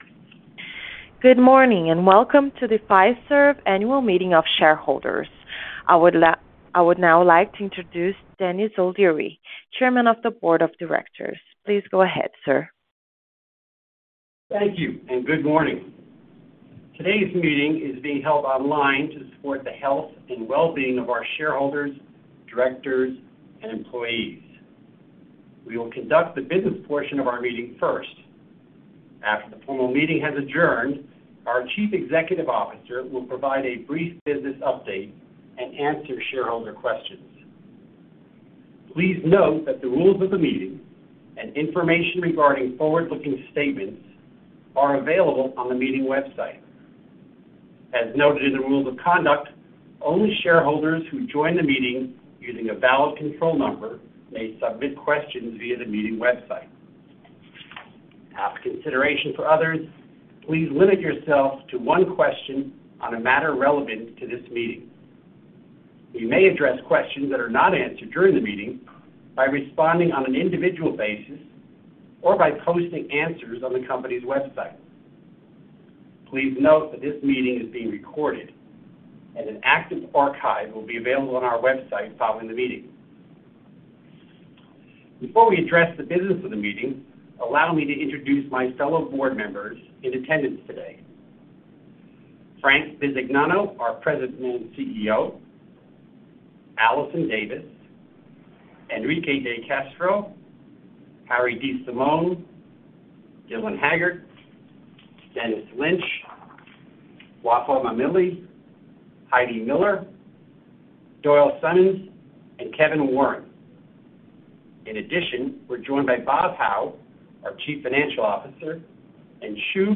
2022 Virtual Annual Meeting of Shareholders